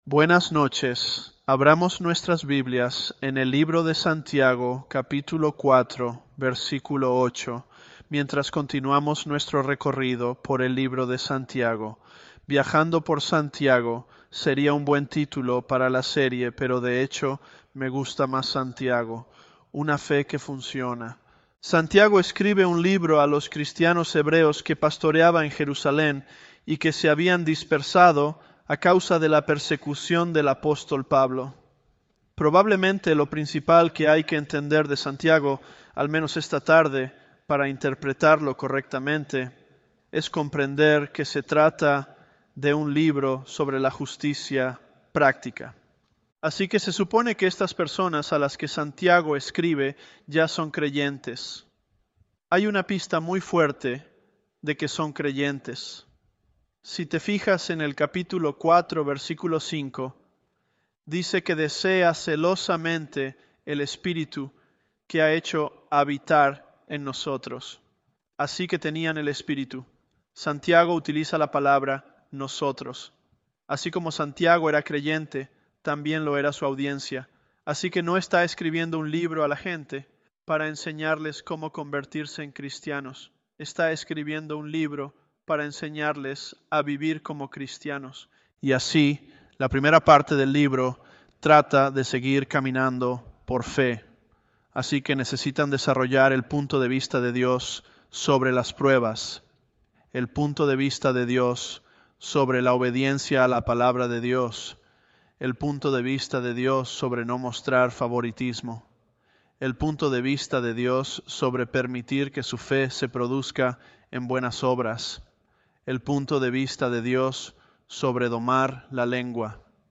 ElevenLabs_James024.mp3